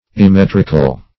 Immetrical \Im*met"ric*al\